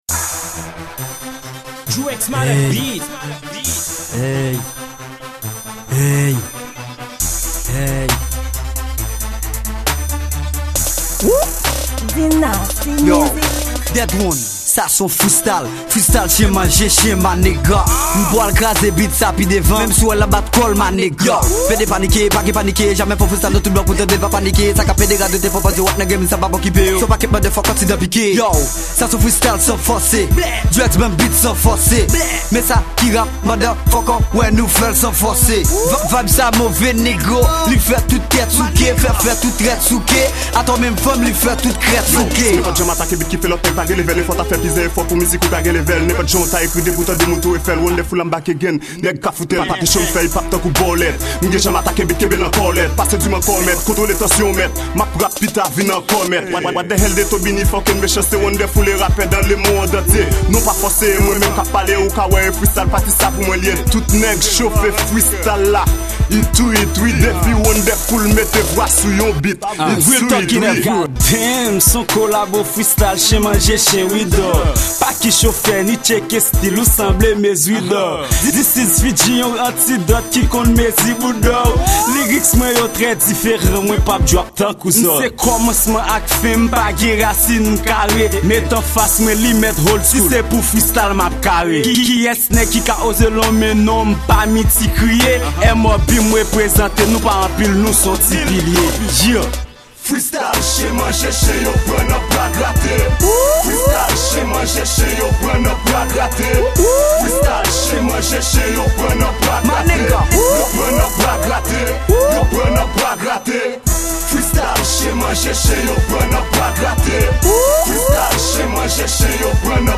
Genre:Rap